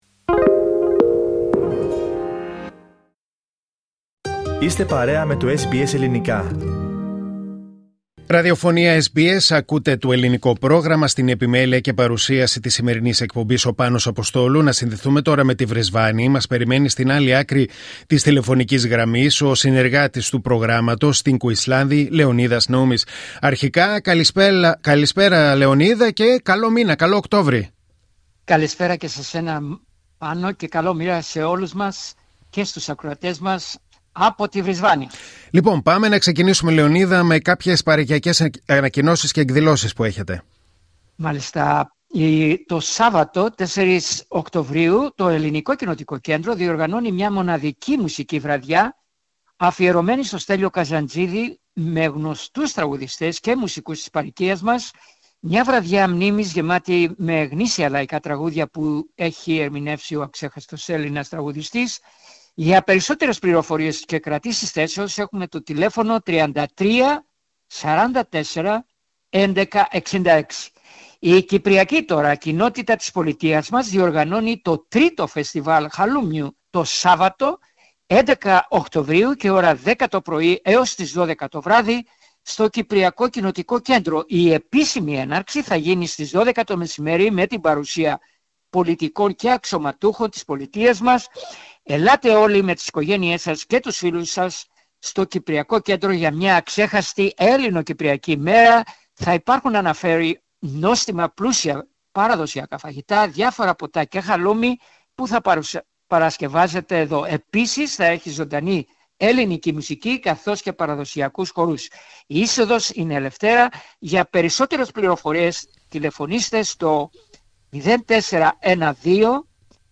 Ακούστε την εβδομαδιαία ανταπόκριση από την Βρισβάνη